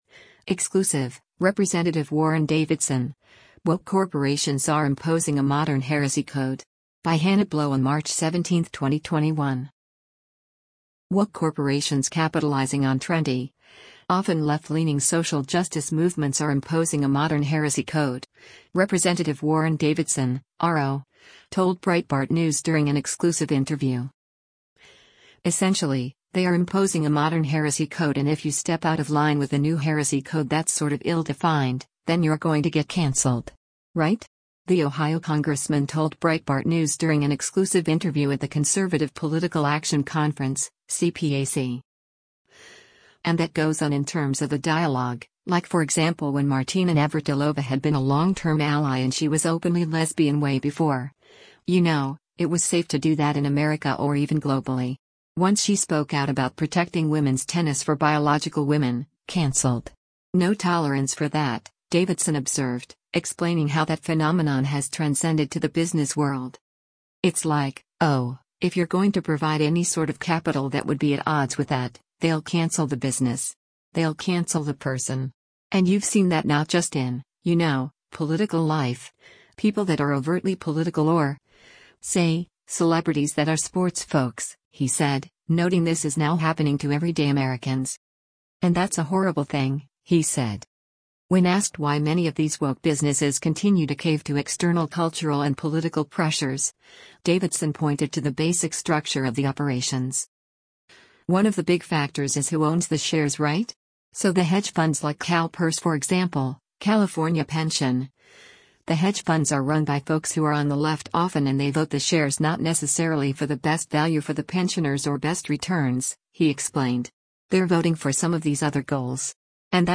“Essentially, they are imposing a modern heresy code and if you step out of line with the new heresy code that’s sort of ill-defined, then you’re going to get canceled. Right?” the Ohio congressman told Breitbart News during an exclusive interview at the Conservative Political Action Conference (CPAC).